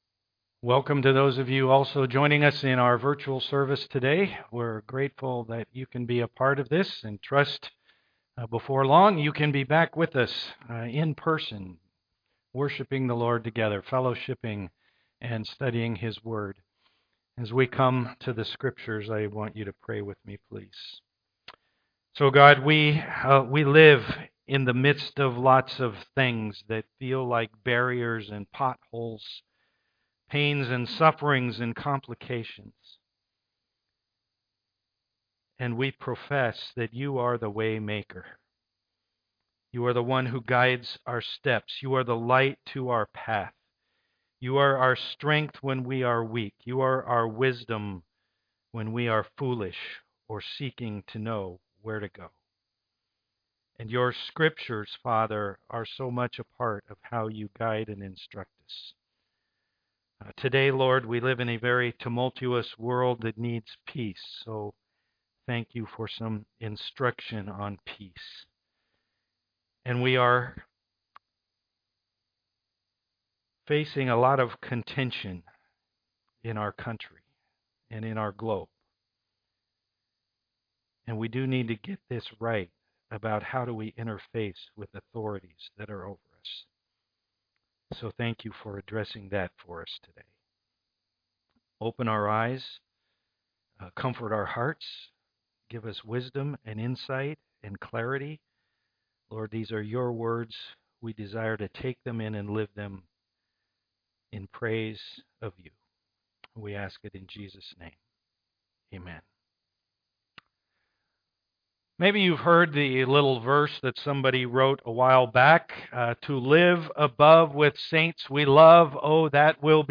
13:1-7 Service Type: am worship The world is crazy.